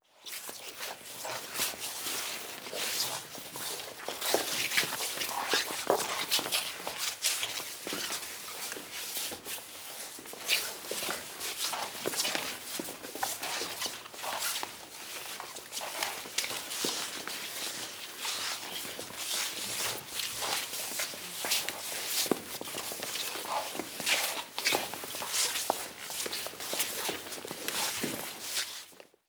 Arrastre de pies al bailar un tango
Sonidos: Acciones humanas